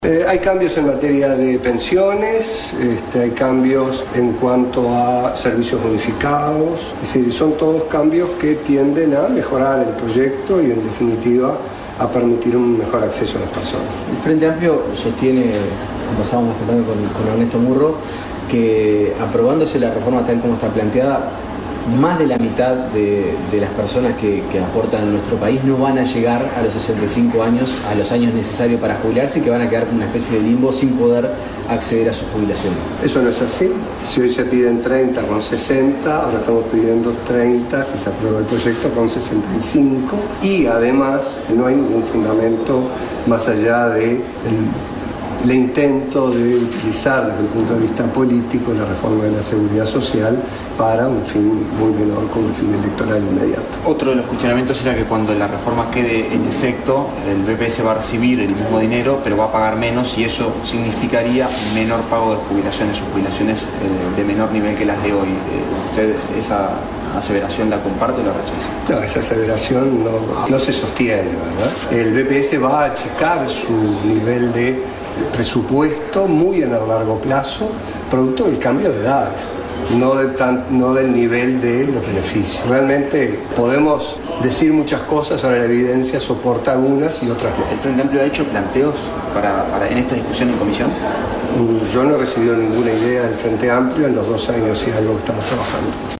“No he recibido ningún planteo del Frente Amplio en los dos años y algo que estamos con esto. No hay un fundamento más allá del intento de frisar desde un punto de vista político la Reforma de la Seguridad Social para un fin electoral inmediato”, dijo Saldain en conferencia de prensa.